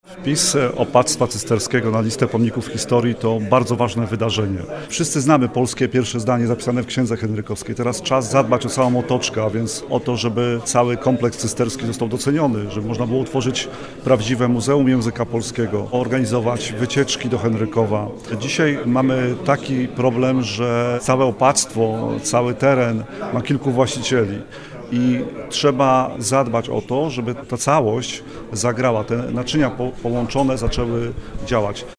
– zaznacza Mariusz Szpilarewicz, burmistrz gminy Ziębice.